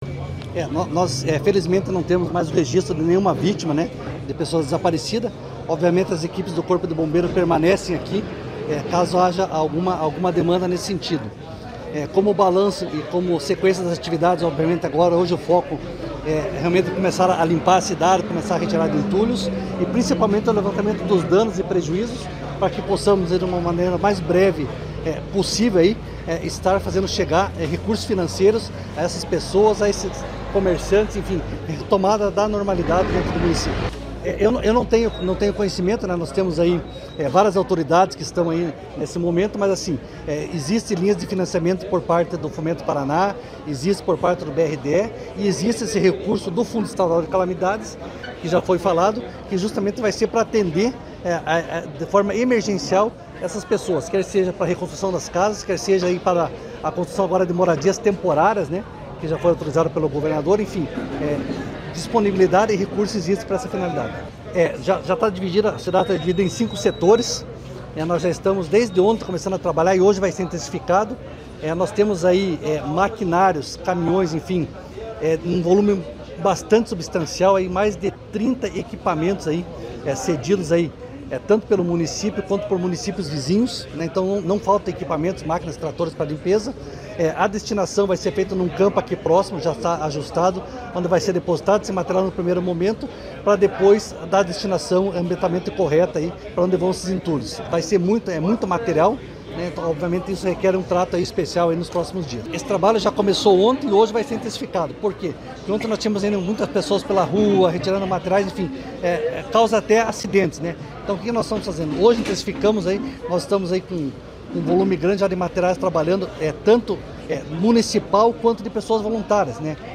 Sonora do coordenador da Defesa Civil Estadual, coronel Fernando Schunig, sobre doações para Rio Bonito do Iguaçu
COLETIVA - FERNANDO SCHUNIG.mp3